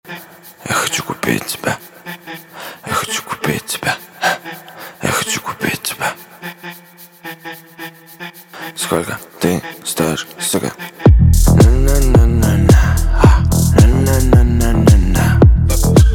• Качество: 192, Stereo
клубняк